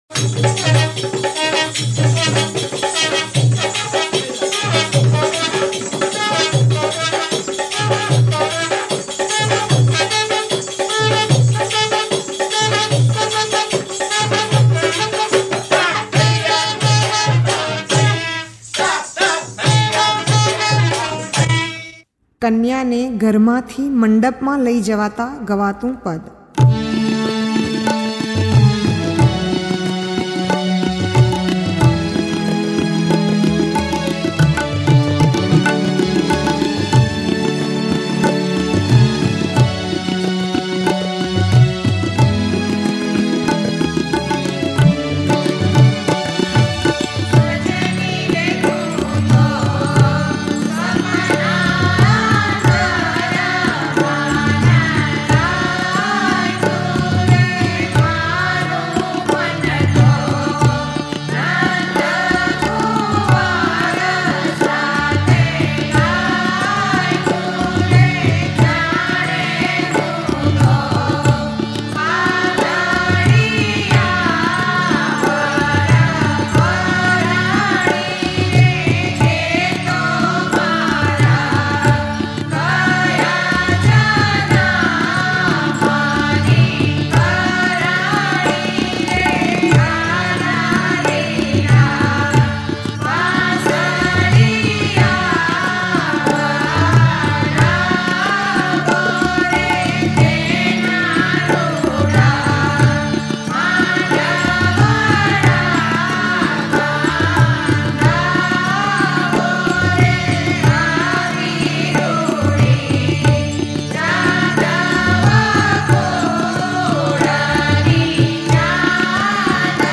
કન્યાને ઘરમાંથી મંડપમાં લઈ જવાતા ગવાતું પદ ...નાદબ્રહ્મ પદ-૭૮૬સજની ...